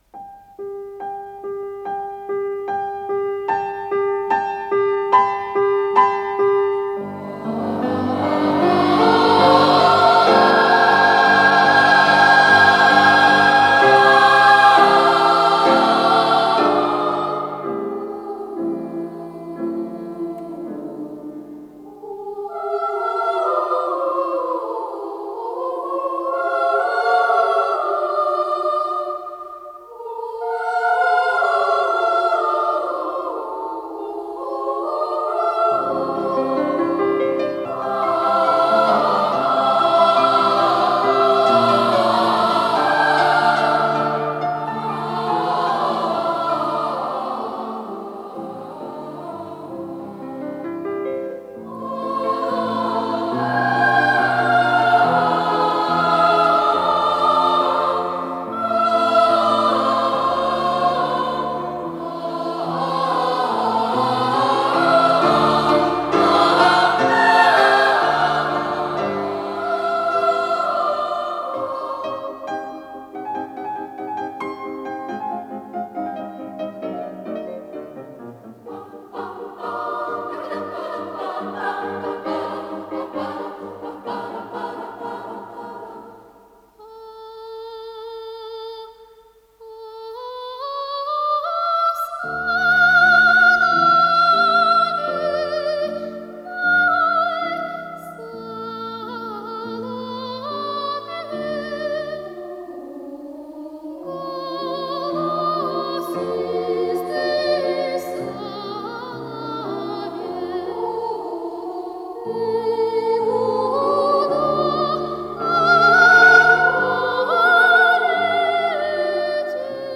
фортепиано
ВариантДубль моно